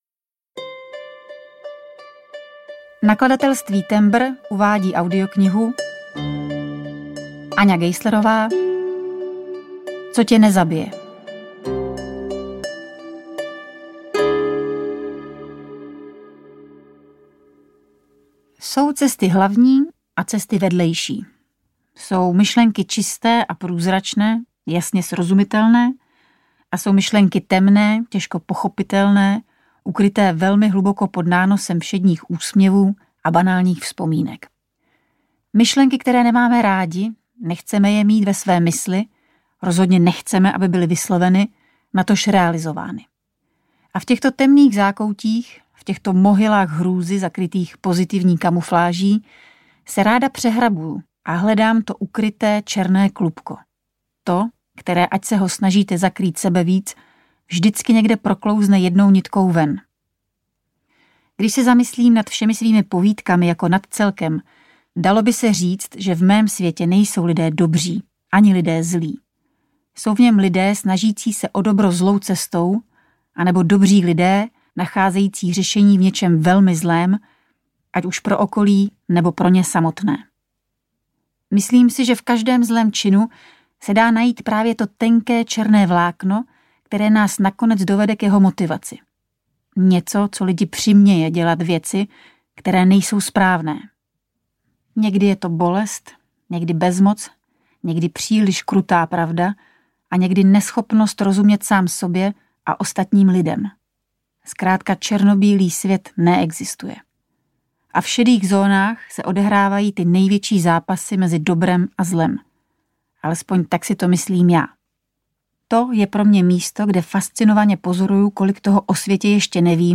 Co tě nezabije audiokniha
Ukázka z knihy
Čte Aňa Geislerová, Martha Issová, Taťjana Medvecká, Jana Plodková, David Novotný a Jan Vlasák
Hudba Roman Holý | Natočeno ve studiu S Pro Alfa CZ
• InterpretAňa Geislerová, Martha Issová, Taťjana Medvecká, Jana Plodková, David Novotný, Jan Vlasák